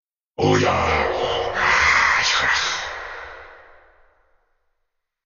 Sfx_animation_krakix_win.ogg